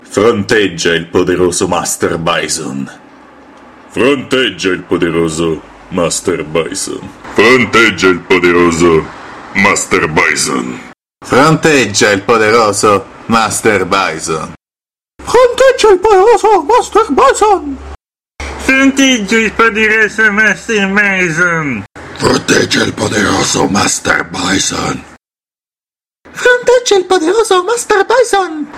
Versatile voice, ready to serve, proper, honest, dutyful, no time-waster.
Sprechprobe: Sonstiges (Muttersprache):